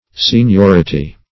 Seniority \Sen*ior"i*ty\, n.